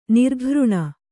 ♪ nirghřṇa